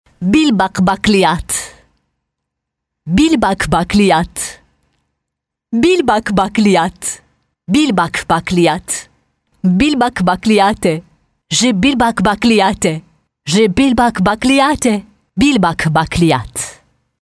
Sprechprobe: Industrie (Muttersprache):
I work as a voice actor in the media industry for 18 years.